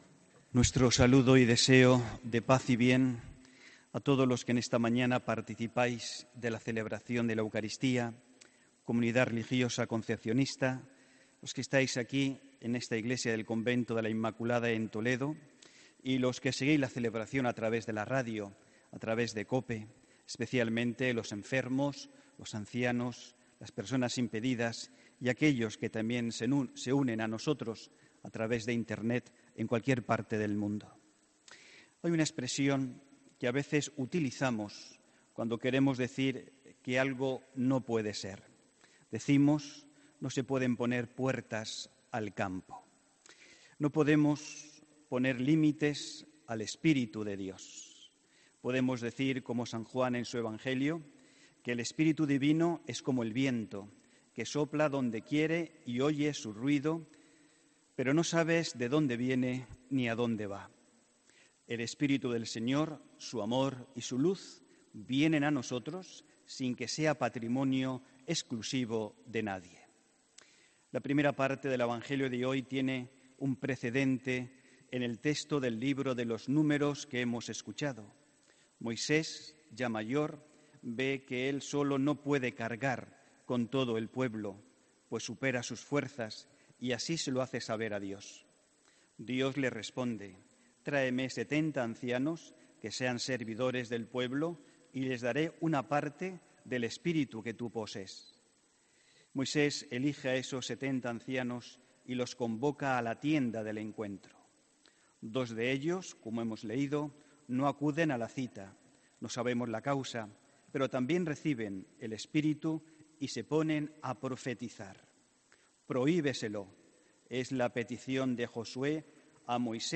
HOMILÍA 30 SEPTIEMBRE